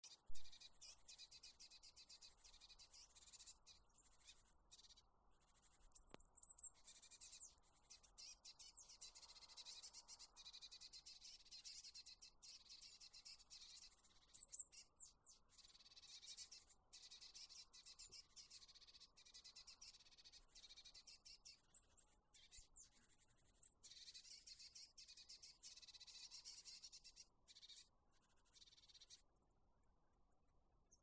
лазоревка, Cyanistes caeruleus
СтатусПосещает кормушку
ПримечанияIespējams, uztraukuma saucieni